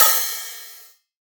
DDW6 OPEN HAT 1.wav